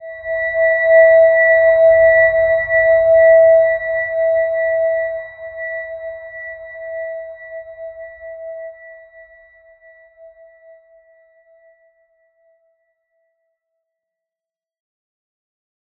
Silver-Gem-E5-mf.wav